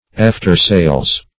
Search Result for " after-sails" : The Collaborative International Dictionary of English v.0.48: After-sails \Aft"er-sails`\ ([a^]f"t[~e]r*s[=a]lz`), n. pl.